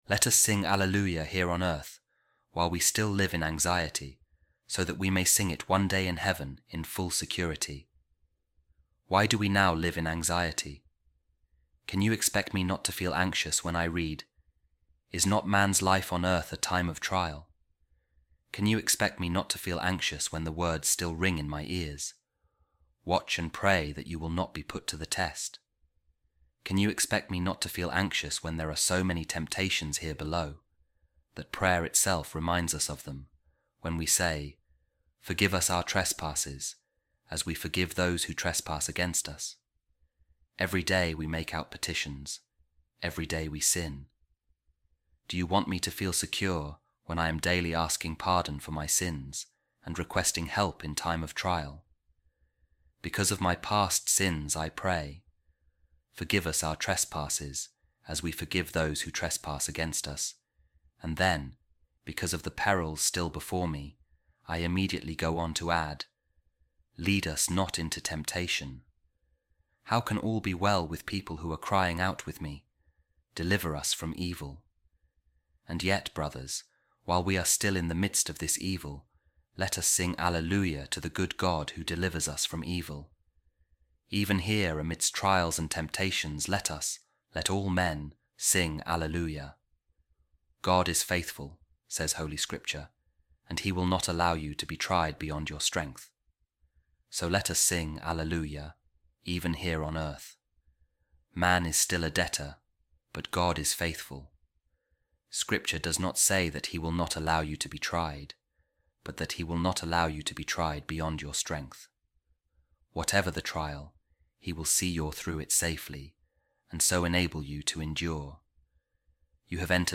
A Reading From A Sermon By Saint Augustine | Let Us Sing Alleluia To God, Who Is Good And Frees Us From Evil